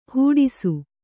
♪ hūḍisu